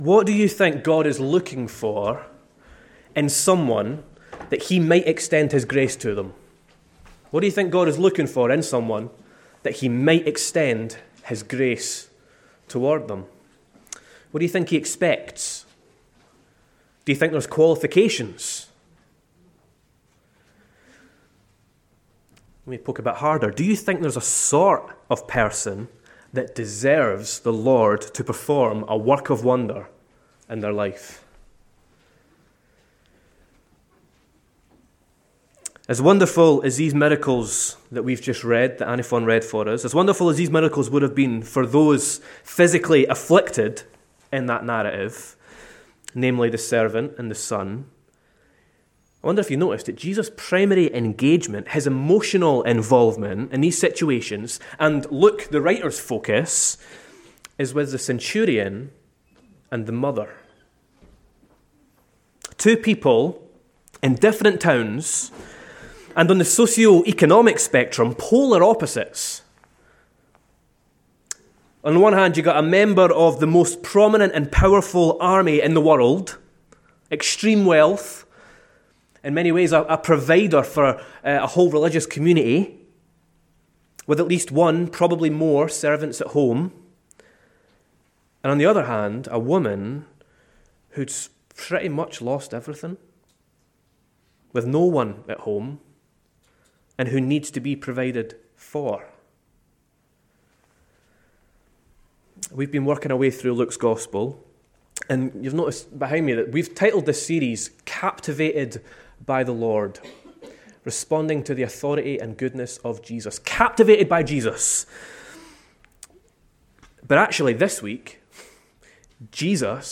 Weekly sermons from Rotherham Evangelical Church, South Yorkshire, UK.